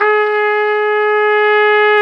Index of /90_sSampleCDs/Roland L-CD702/VOL-2/BRS_Tpt 5-7 Solo/BRS_Tp 5 RCA Jaz